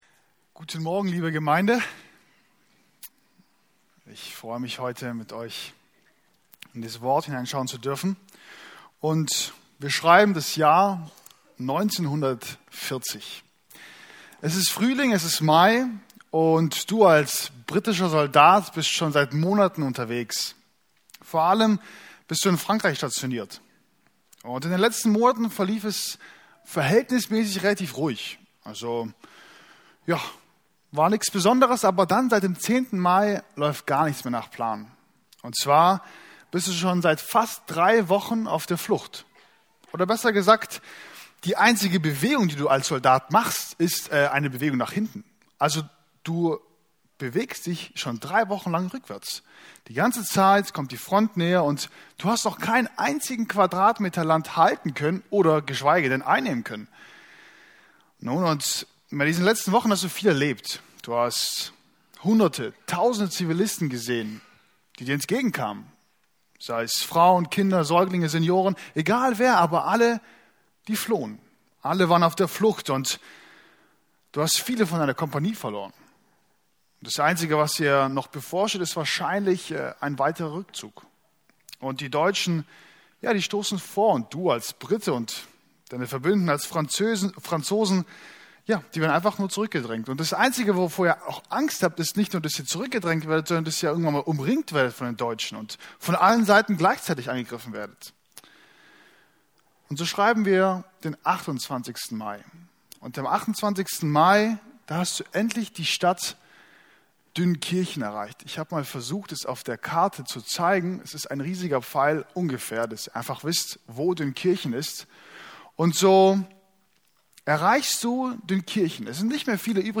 Passage: Josua 1, 9 Dienstart: Predigten